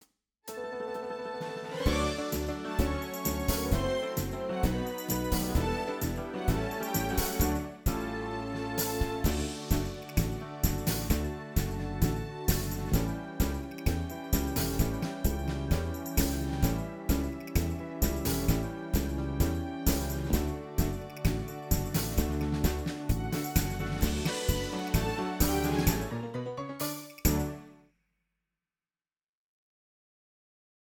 Drums from Toontrack Superior Drummer 2
Percussions from Musyng Kite GM.sfpack
Accordion from Accordions Library for Kontakt. All the rest from sampled Motif XF.
The demos itselves sound quite good.